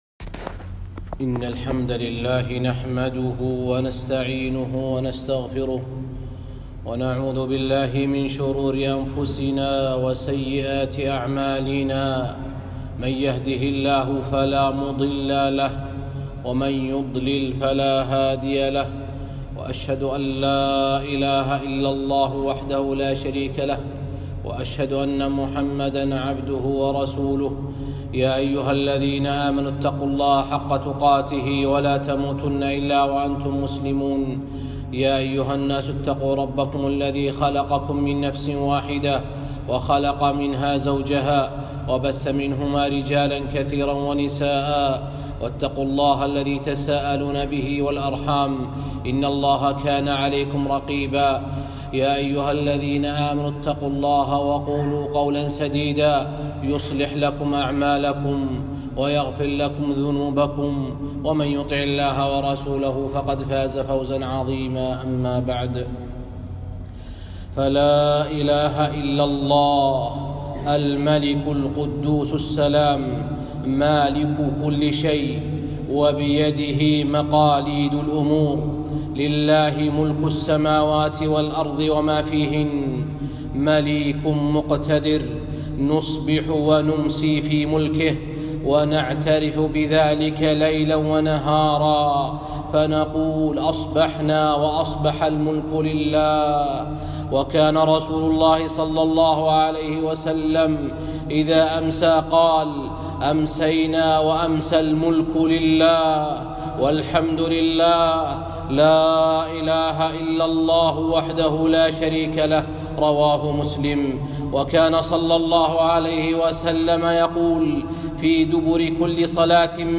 خطبة - المُلْكُ لله الواحد القهار - دروس الكويت